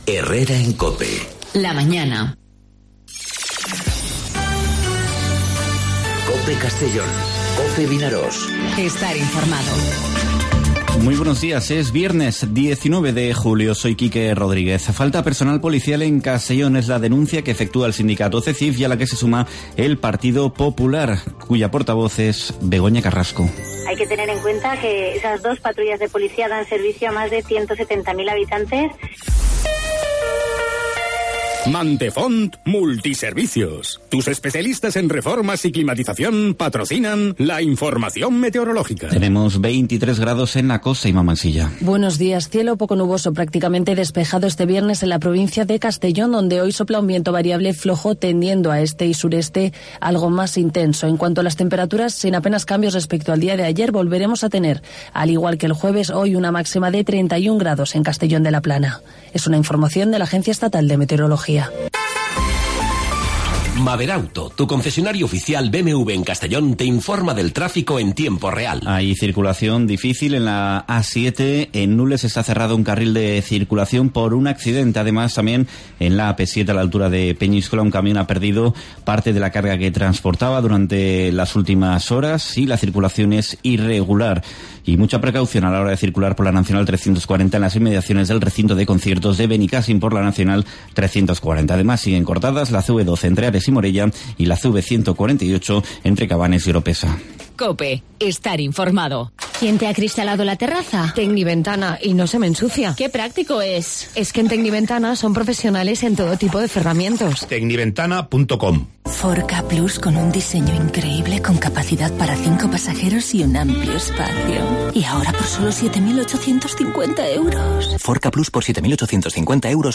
Informativo 'Herrera en COPE' Castellón (19/07/2019)